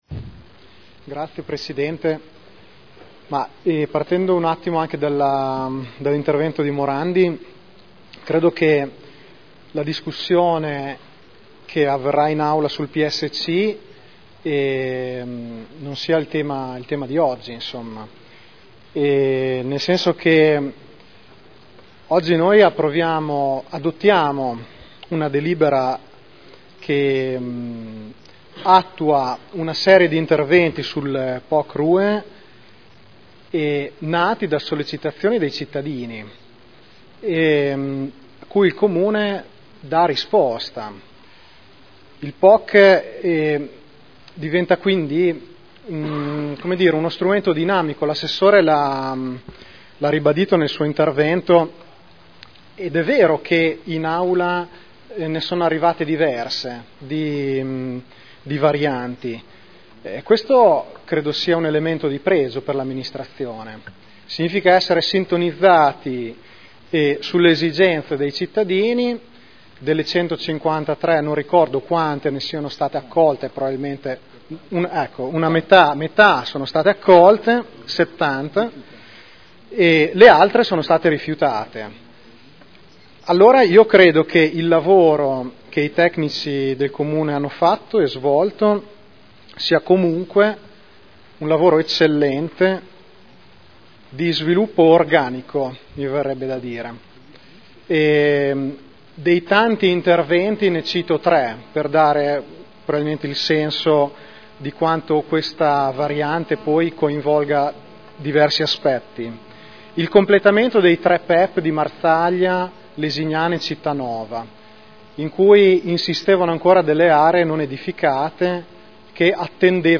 Seduta del 09/01/2012. Delibera: Variante al Piano Operativo Comunale (Poc) e al Regolamento Urbanistico Edilizio (Rue) – Adozione (Commissione consiliare del 20 dicembre 2011)